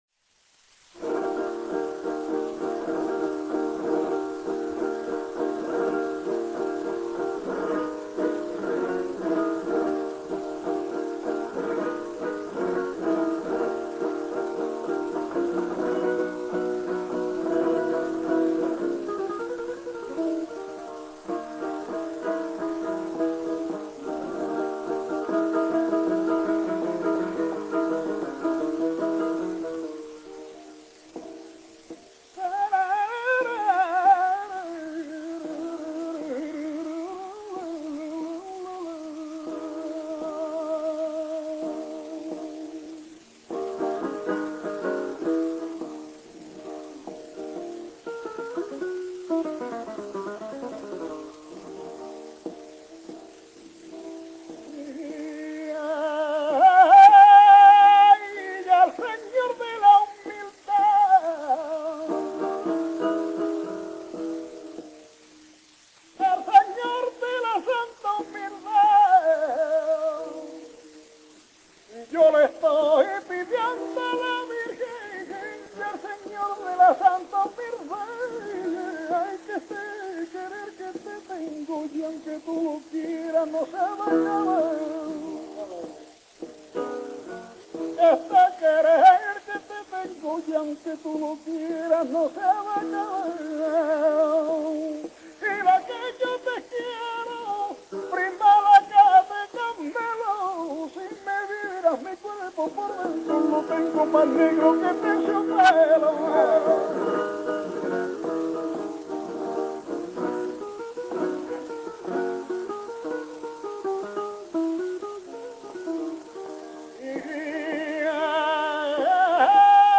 chant
guitare